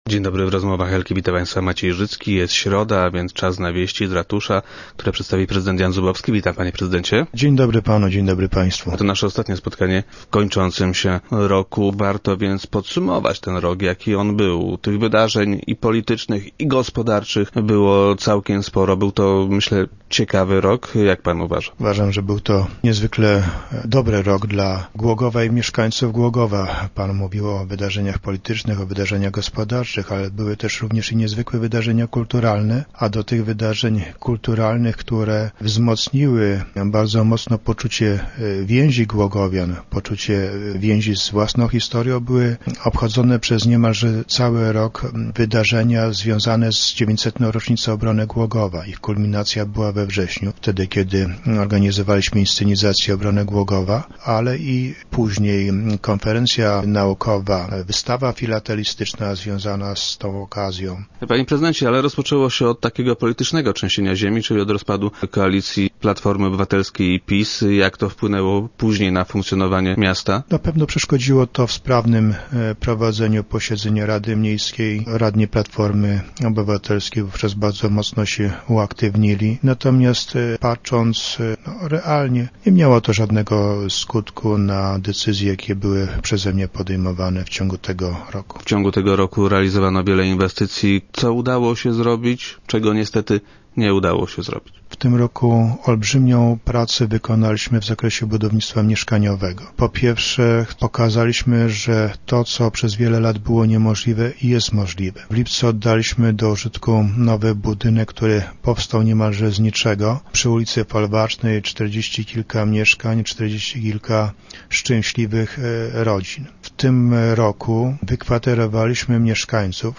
Wydarzenia kończącego się roku, prezydent Zubowski podsumował w dzisiejszych Rozmowach Elki.